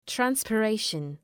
Shkrimi fonetik{,trænspı’reıʃən}
transpiration.mp3